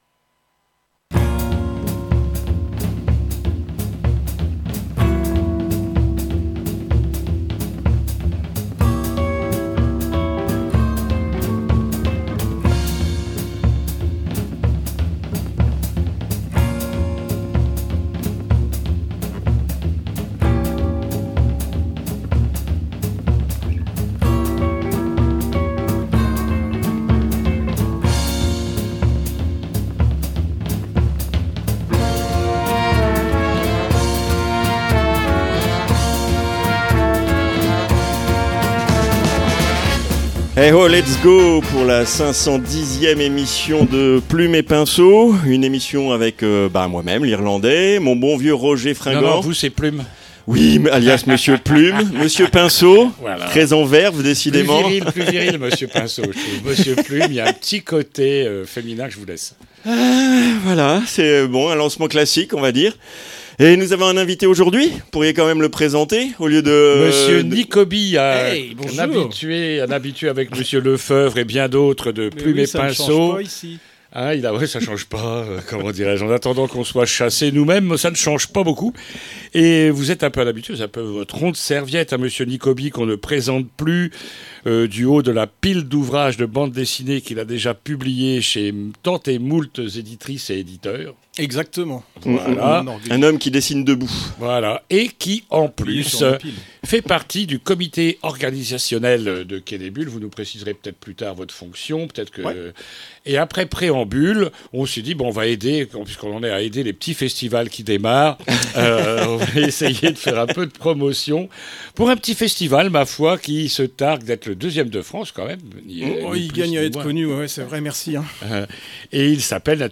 I- INTERVIEW La 44 ème édition du festival Quai des bulles aura lieu les 24 , 25 et 26 octobre 2025 à Saint Malo